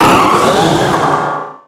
Cri de Méga-Oniglali dans Pokémon Rubis Oméga et Saphir Alpha.
Cri_0362_Méga_ROSA.ogg